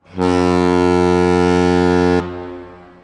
LongBlast2.mp3